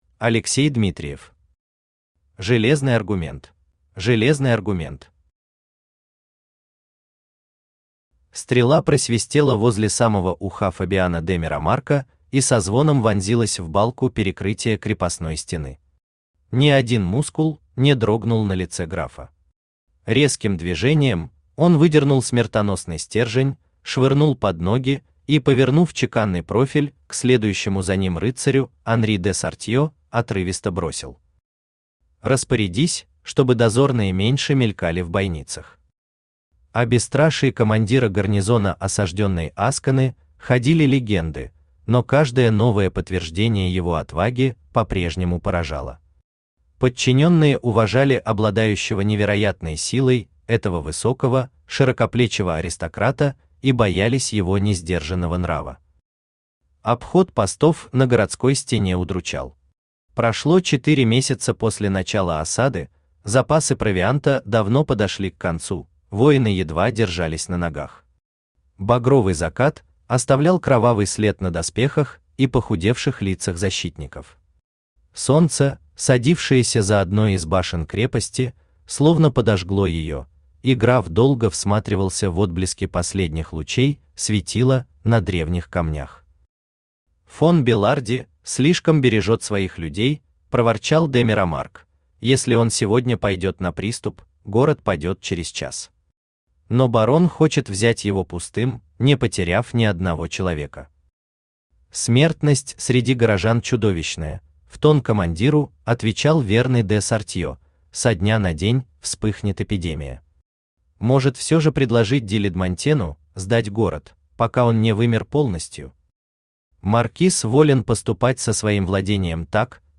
Аудиокнига Железный аргумент | Библиотека аудиокниг
Aудиокнига Железный аргумент Автор Алексей Дмитриев Читает аудиокнигу Авточтец ЛитРес.